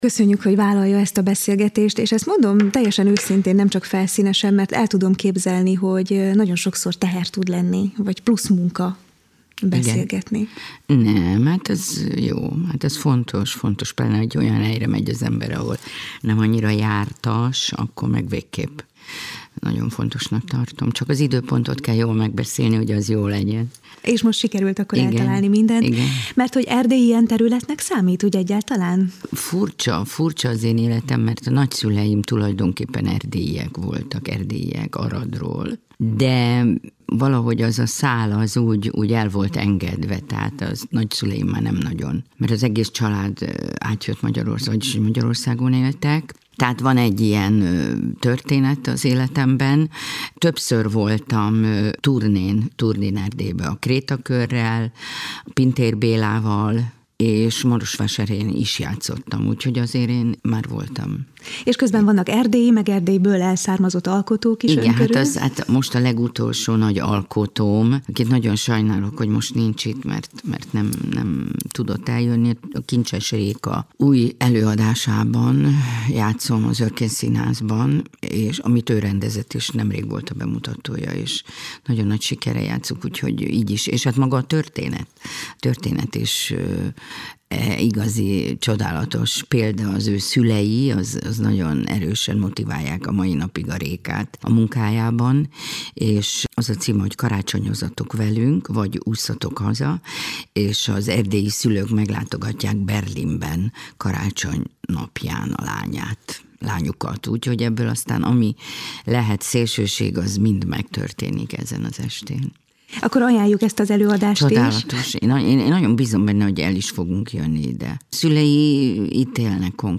Csakanyi-Eszter-interju-1.mp3